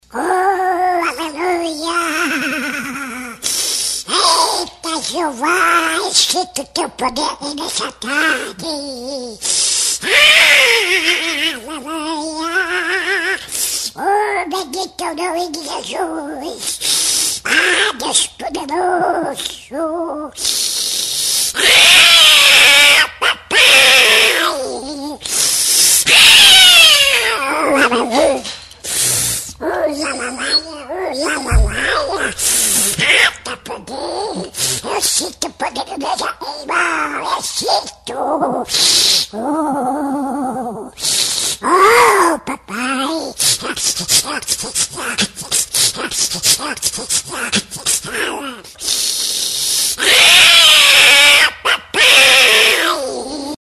Toque de notificação do WhatsApp Toque do Pato Donald
Categoria: Toques